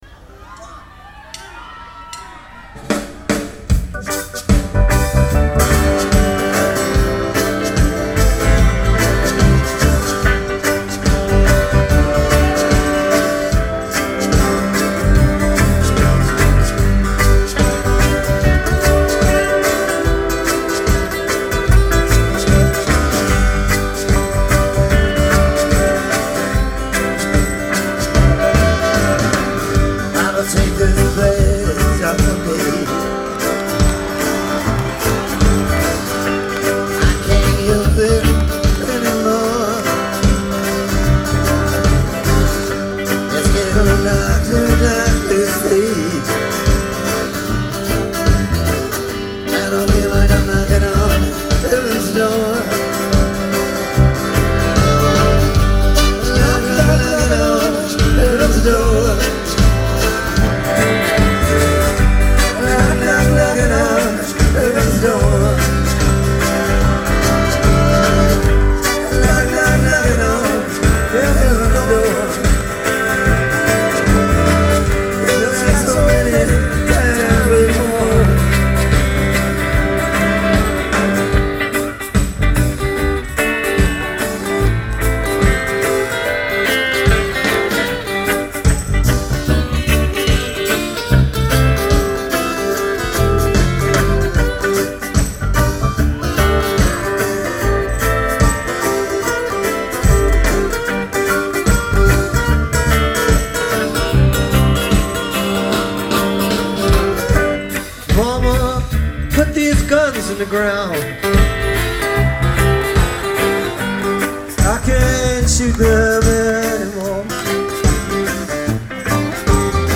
Fun live duet of with and in Duluth on July 3, 1999.